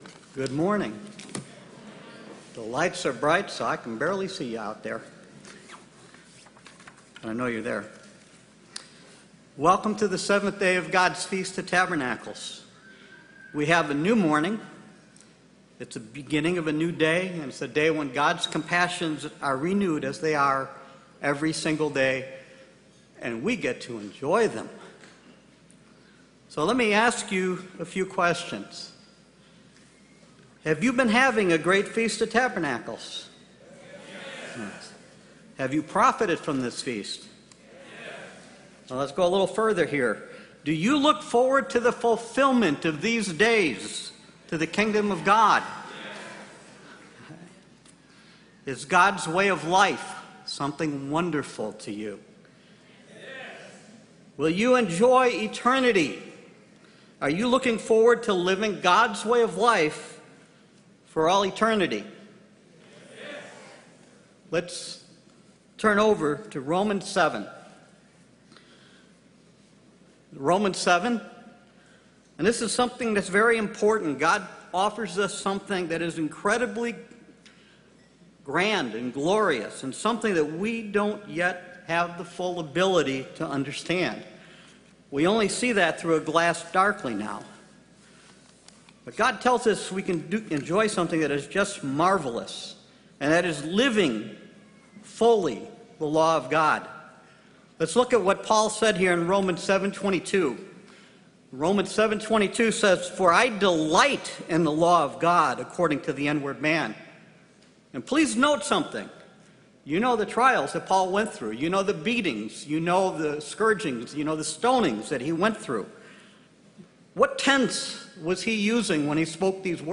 This sermon was given at the Lake Junaluska, North Carolina 2021 Feast site.